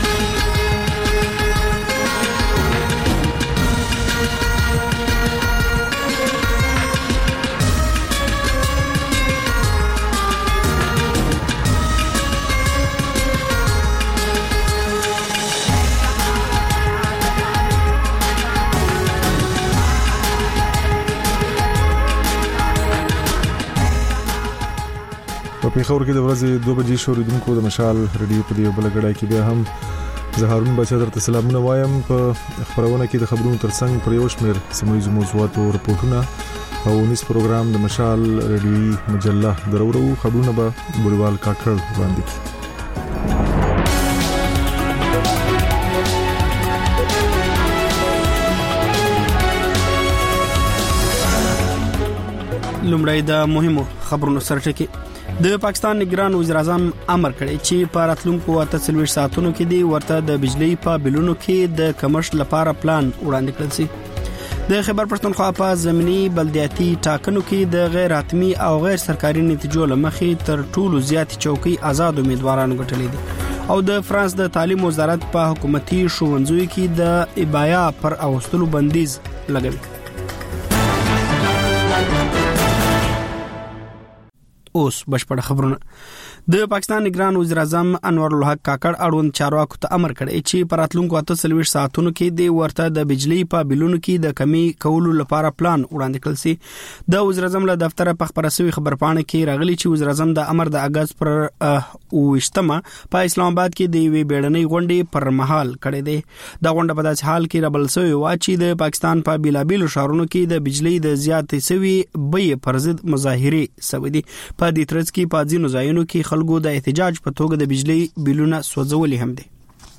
د مشال راډیو دویمه ماسپښینۍ خپرونه. په دې خپرونه کې لومړی خبرونه او بیا ځانګړې خپرونې خپرېږي.